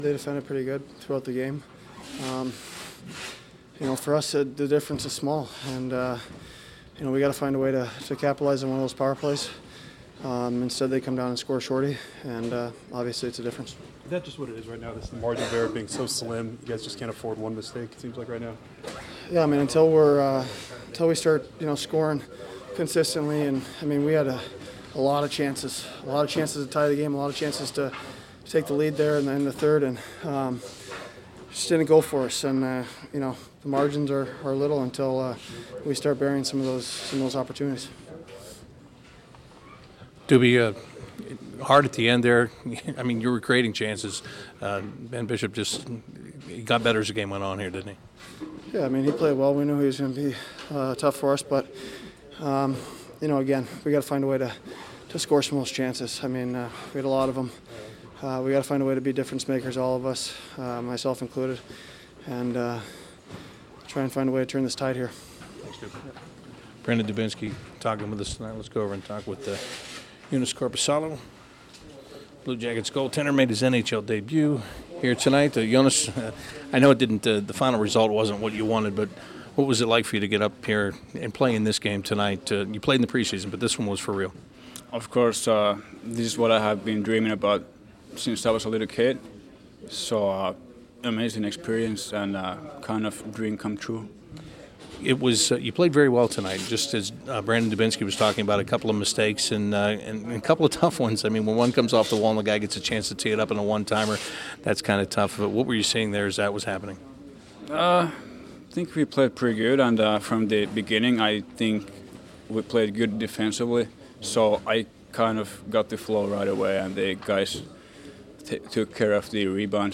Columbus Blue Jackets in the locker room after their 2-1 loss to the Tampa Bay Lightning